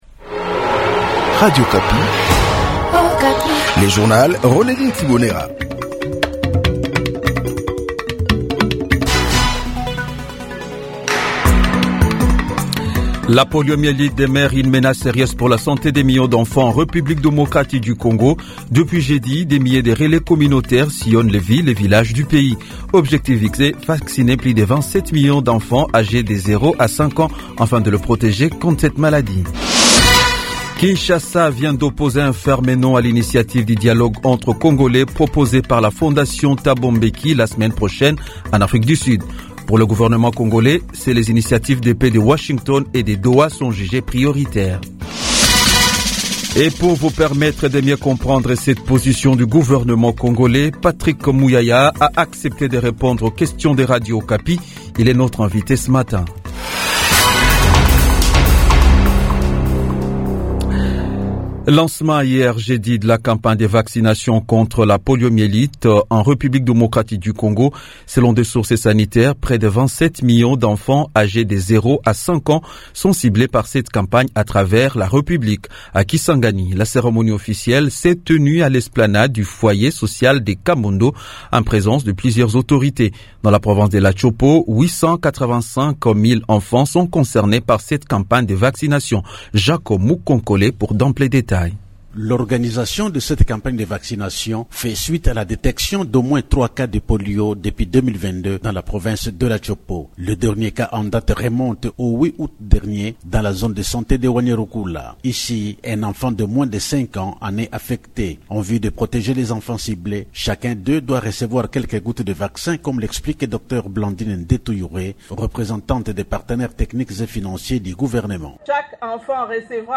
Jounal matin 6h